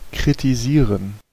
Ääntäminen
France: IPA: [ʁǝ.pʁɑ̃dʁ]